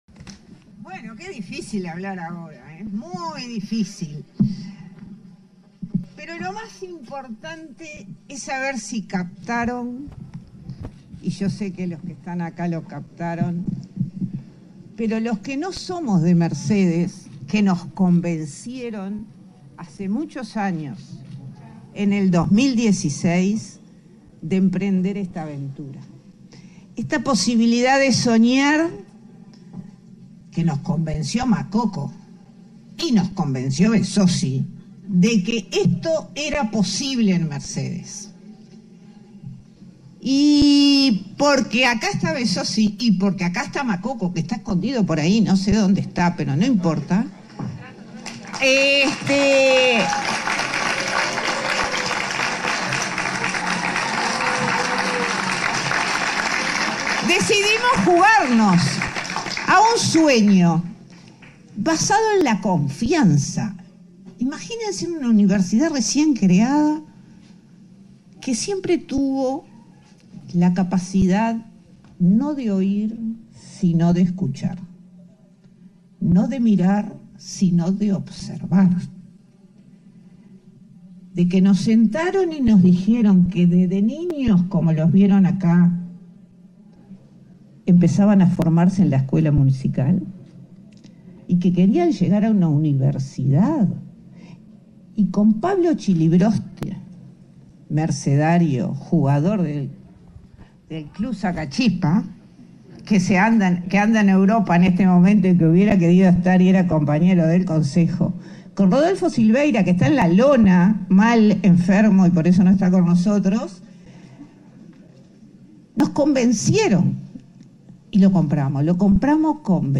En el marco de la ceremonia de inauguración de las obras de ampliación de la sede de la Universidad Tecnológica del Uruguay (UTEC) en Mercede, se